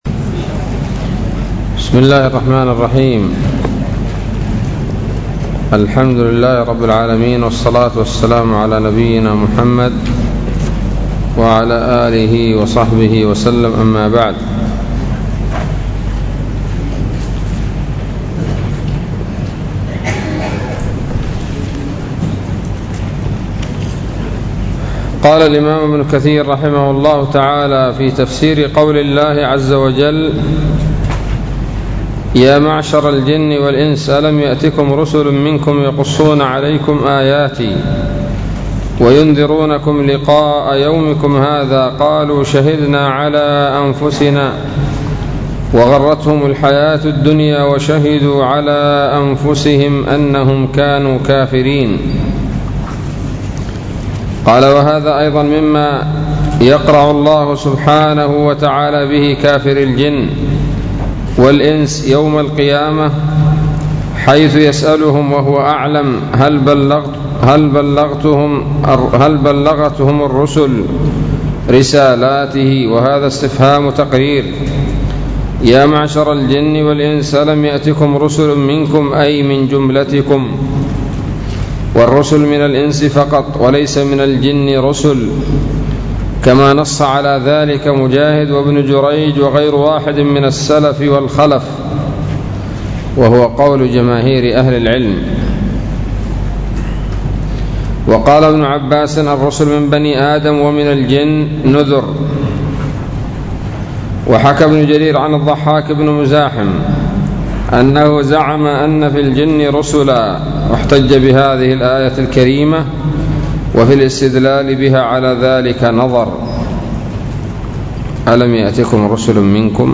الدرس الحادي والخمسون من سورة الأنعام من تفسير ابن كثير رحمه الله تعالى